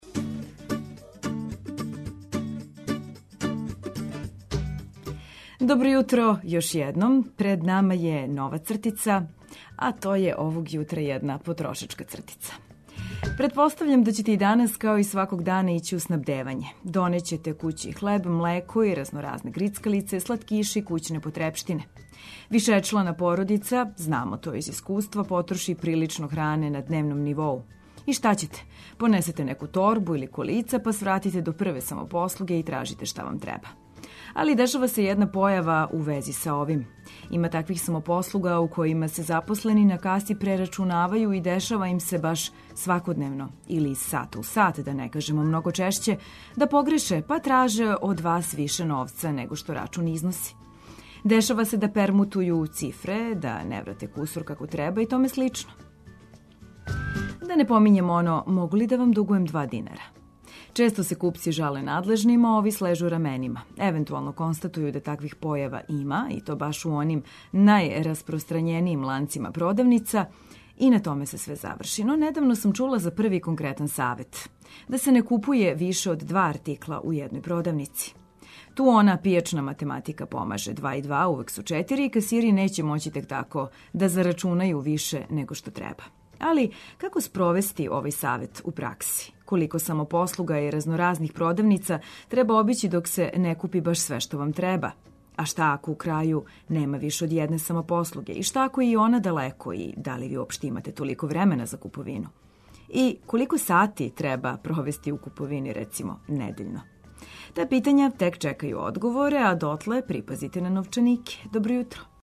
Распитујемо се зашто је на земунској пијаци продаја пилећег меса организована у нехигијенским условима, а наш репортер јавиће се из Карађорђеве улице у Београду где су у току радови на реконструкцији ова важне саобраћајнице.